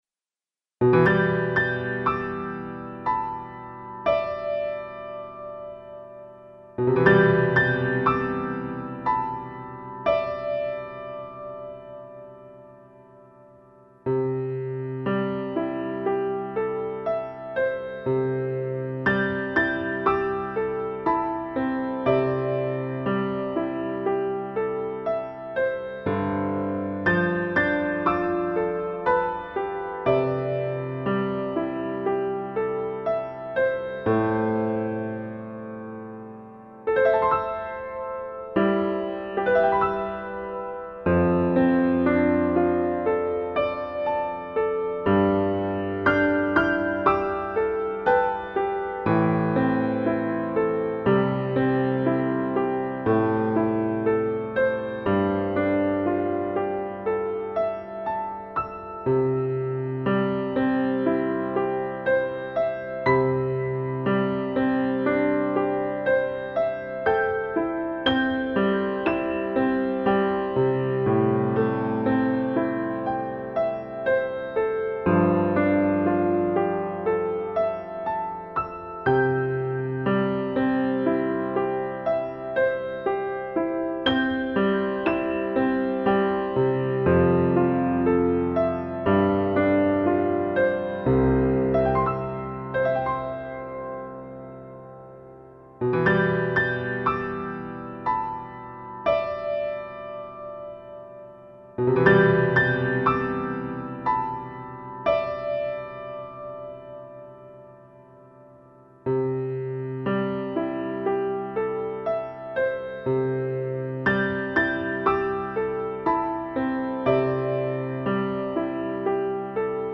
C调伴奏：